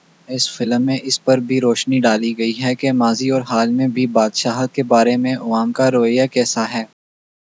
deepfake_detection_dataset_urdu / Spoofed_TTS /Speaker_02 /275.wav